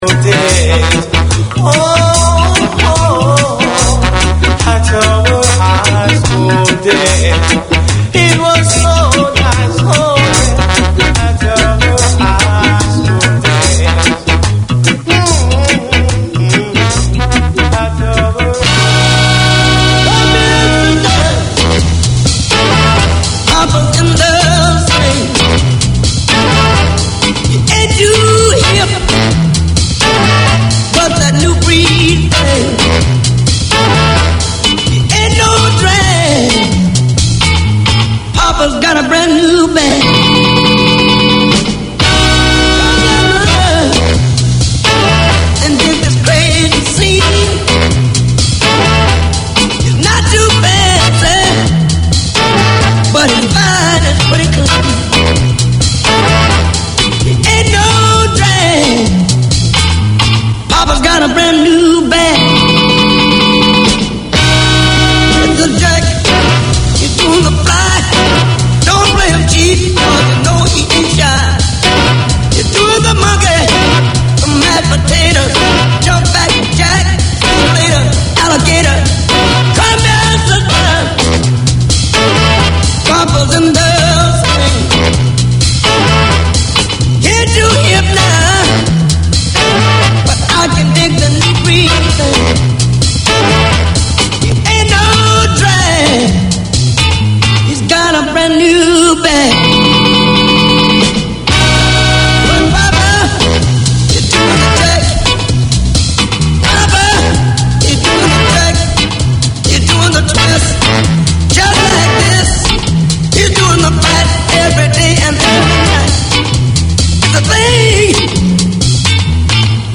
Each week we discuss the devotional teachings of Lord Sri Krishna based on Srimad Bhagwad Gita. We examine the issues people face in the present age of Kali Yuga and its solutions based on Srimad Bhagwad. The talk is highlighted with the playing of bhjans.